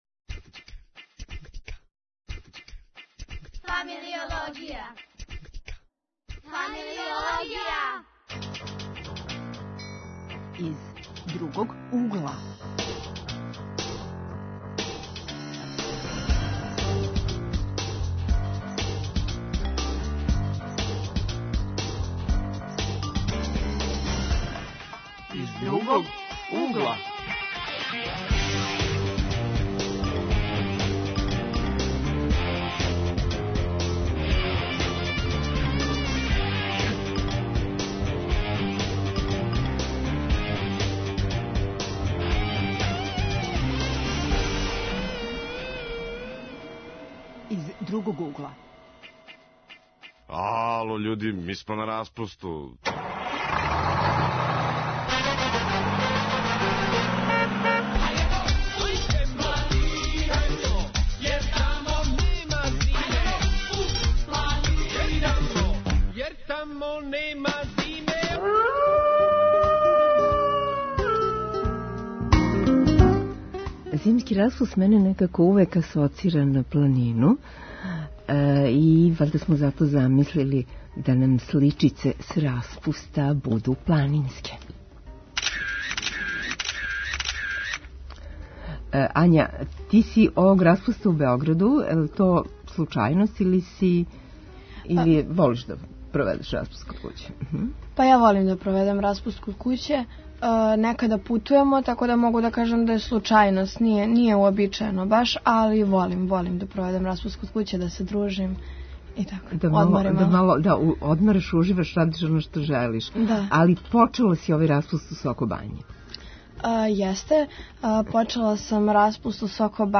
Гости у студију су средњошколци који распуст проводе у Београду.